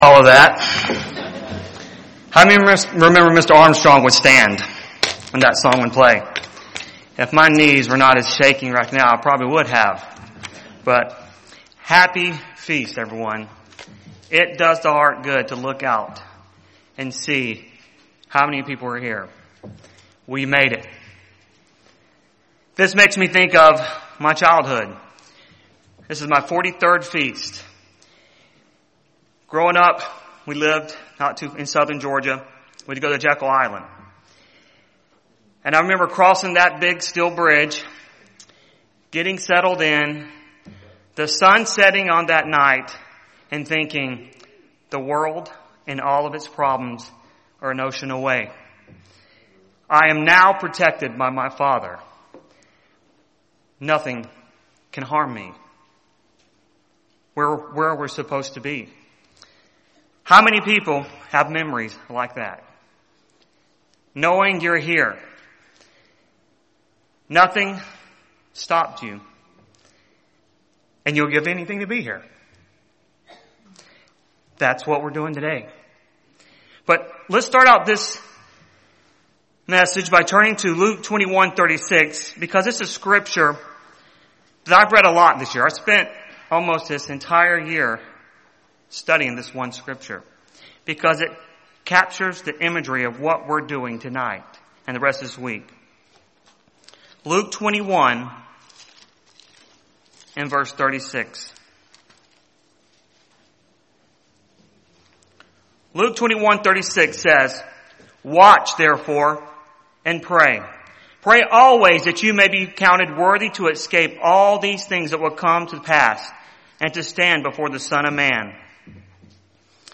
This sermon was given at the Cincinnati, Ohio 2020 Feast site.